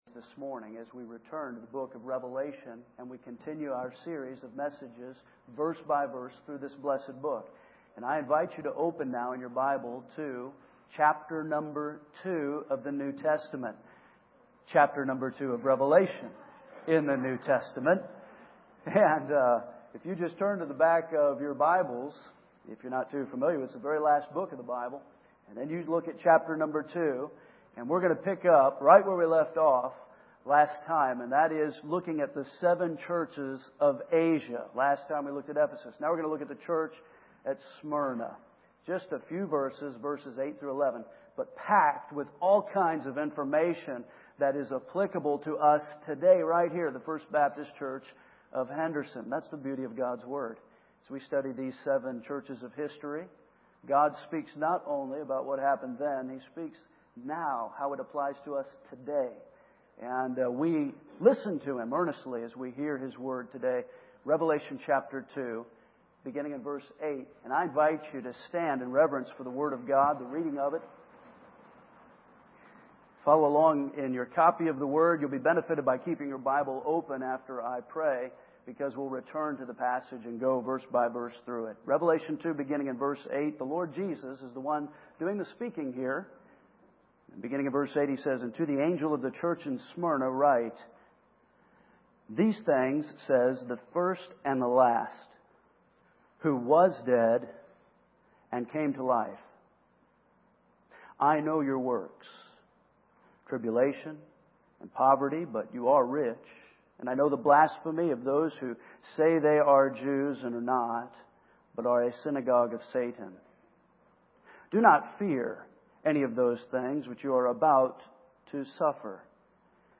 We are continuing our morning series of messages through the book of Revelation, verse-by-verse, and we find ourselves this morning in chapter 2, verses 8-11.